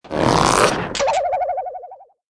mailbox_full_wobble.mp3